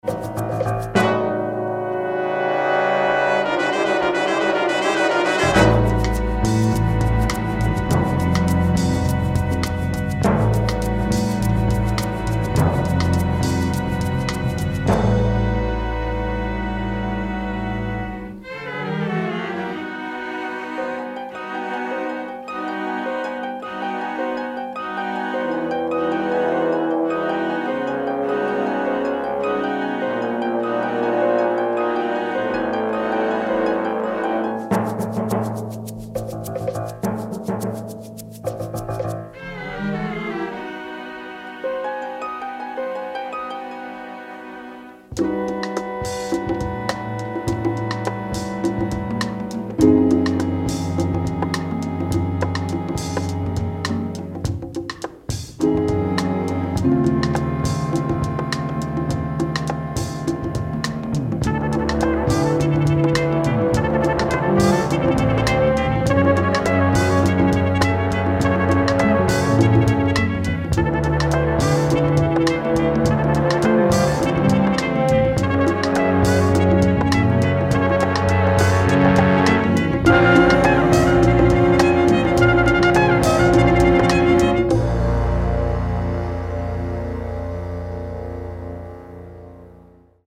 swinging orchestral soundscape